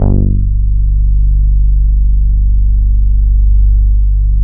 45 BASS   -R.wav